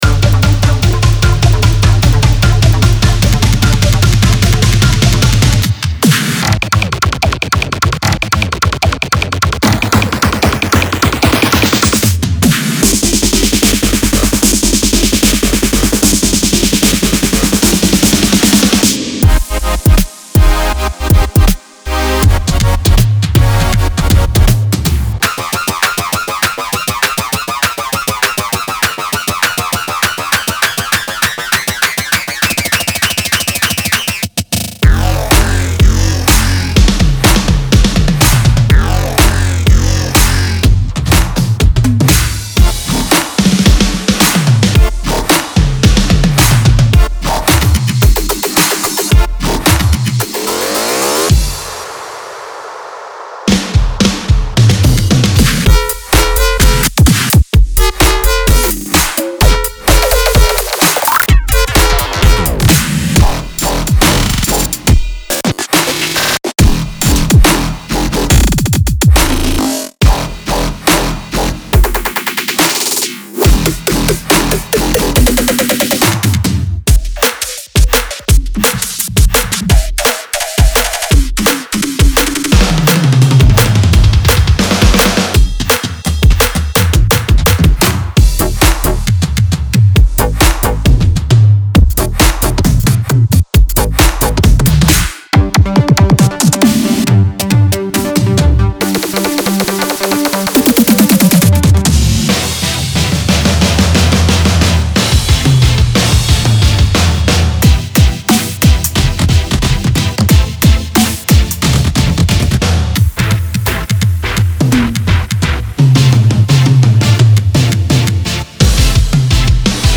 Drum Fills which include 1 to 2 bar long drum fills in 11 different BPM’s ranging from 85bpm to 170bpm. The Drum Fills FX folder are sound bending, mind melting, effected Drum Fills that add innovation beyond the phat crisp drum hits of the non-effected folder.
Get ready to make some next level and highly engaging sequences for any variety of electronic dance music!
24 bit WAV Stereo
MP3 DEMO